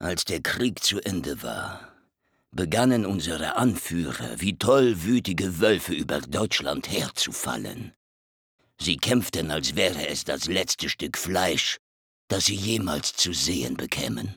For the game's two protagonists, Reznov and Mason, Effective Media employed well-known dubbing actors Udo Schenk and Tobias Kluckert, who engage in veritable battles of words during the course of the game.